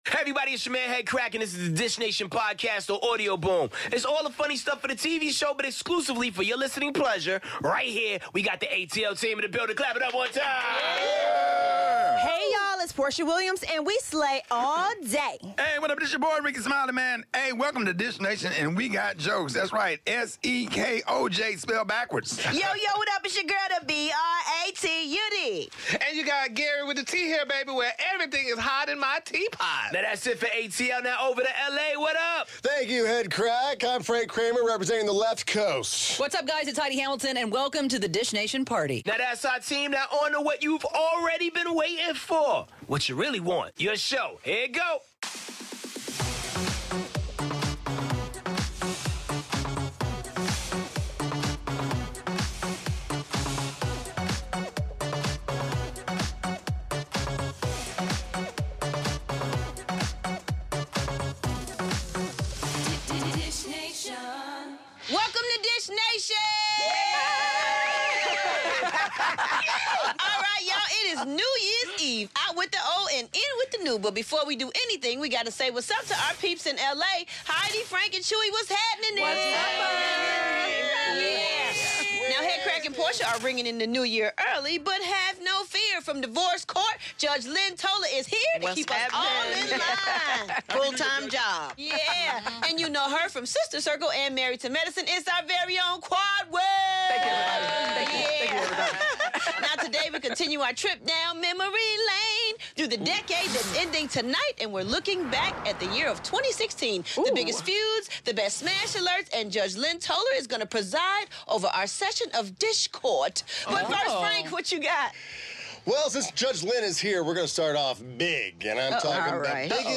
🎆Judge Lynn Toler is in studio and we're dishin' on the year 2016! Kelly Ripa and Taylor Swift have something in common 👀plus, which celebrity won the year, Leonardo DiCaprio or Beyoncé?!